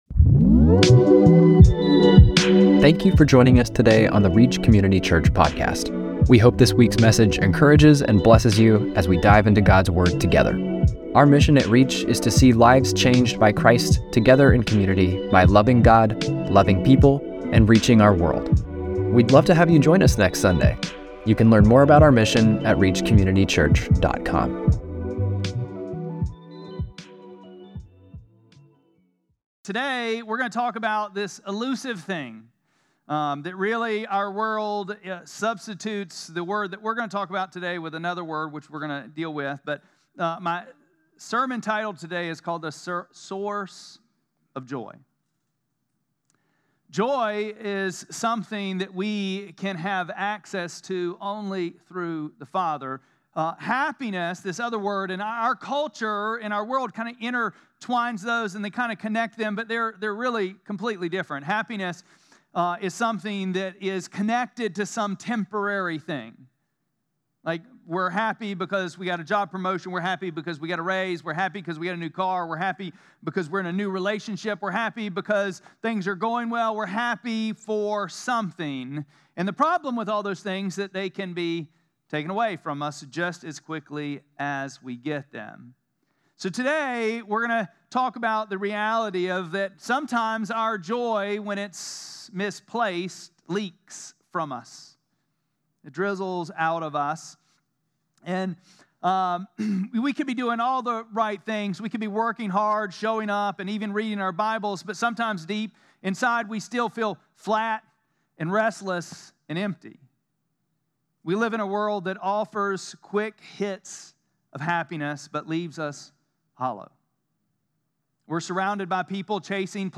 6-1-25-Sermon.mp3